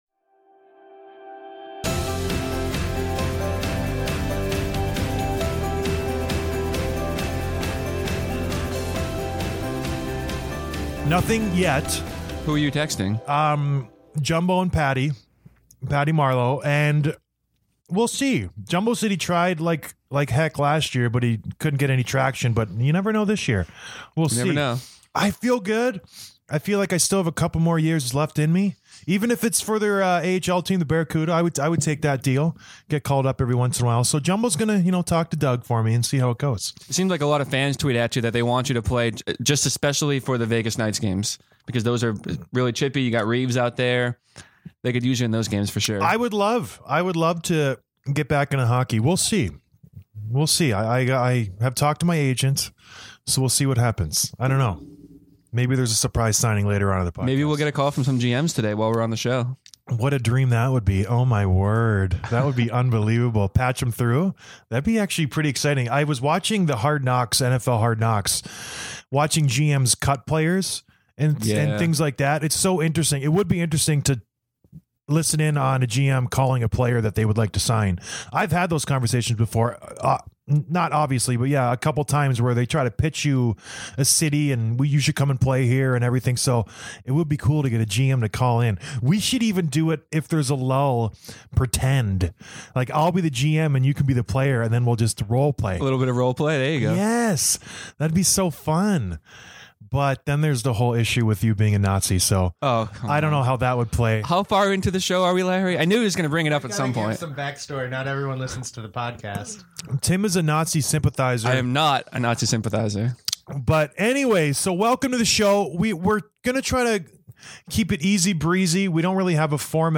We were LIVE covering all the big signings, trade, rumors, and more to kick off free agency. Thanks to everyone who tuned in for our awesome 2 hour live stream.